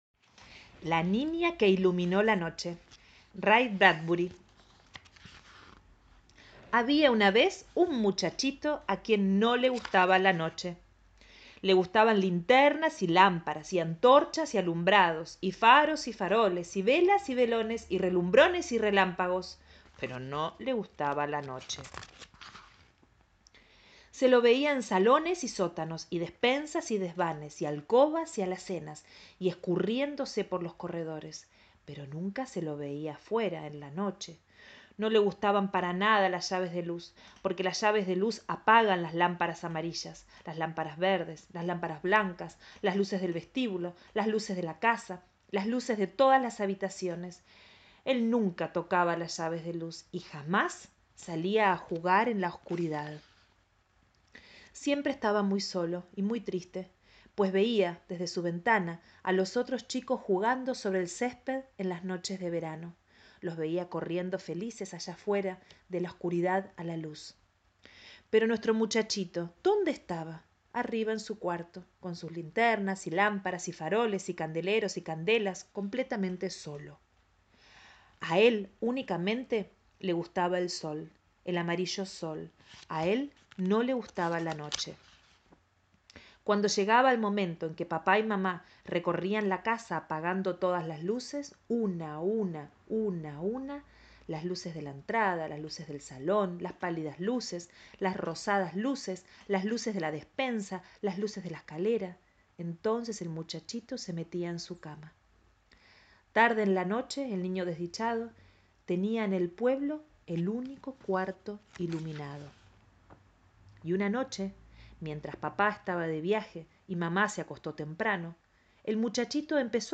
narradora de literatura para la infancia